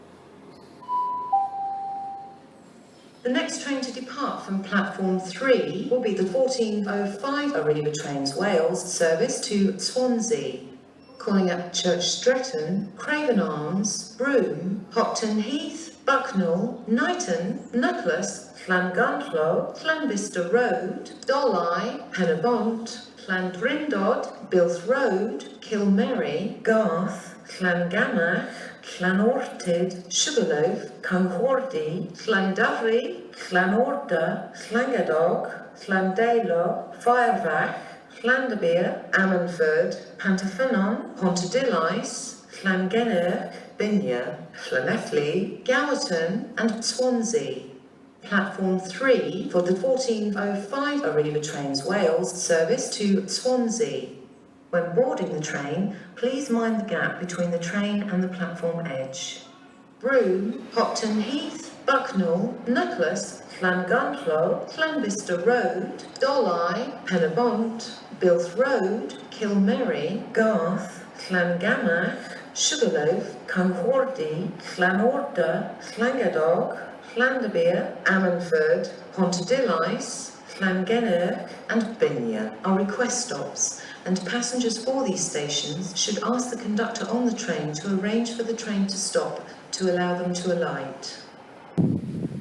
announcing a train service on the Heart of Wales route